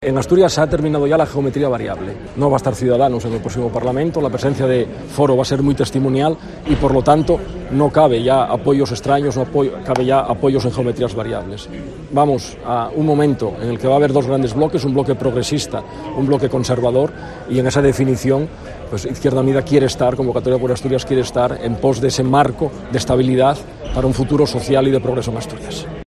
El candidato de IU ha hecho estas declaraciones en un acto de campaña en Langreo en el que ha estado acompañado por la portavoz federal y eurodiputada de IU, Sira Rego, que ha recordado que la coalición es la segunda fuerza municipalista de la comunidad y que confía en que en los próximos comicios aumente su presencia en los municipios.